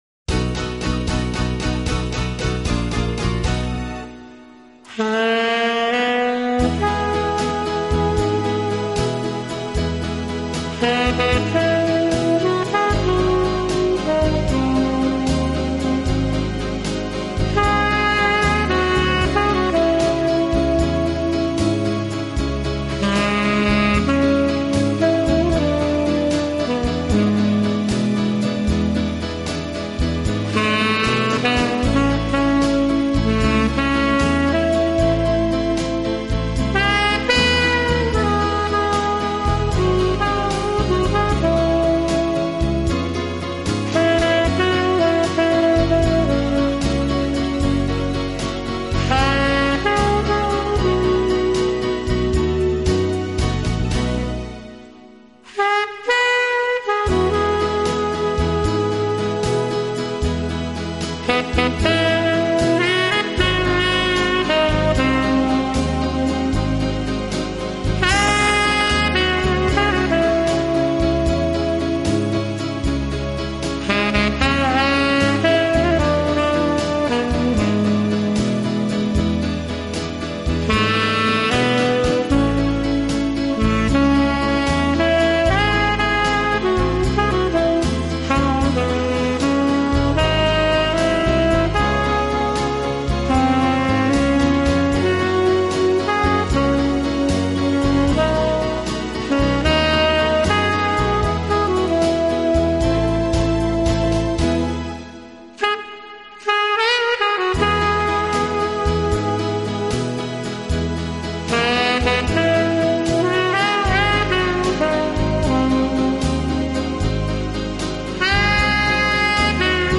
及其它类型音乐中，表现出杰出的抒情，浪漫风格普遍受到人们的喜爱。